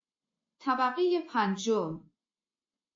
دانلود آهنگ طبقه پنجم از افکت صوتی اشیاء
جلوه های صوتی
برچسب: دانلود آهنگ های افکت صوتی اشیاء دانلود آلبوم صدای اعلام طبقات آسانسور از افکت صوتی اشیاء